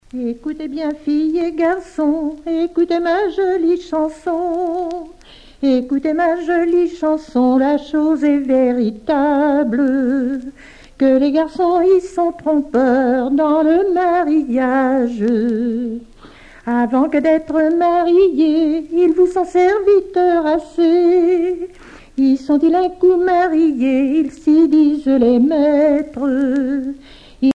Genre dialogue
Pièce musicale éditée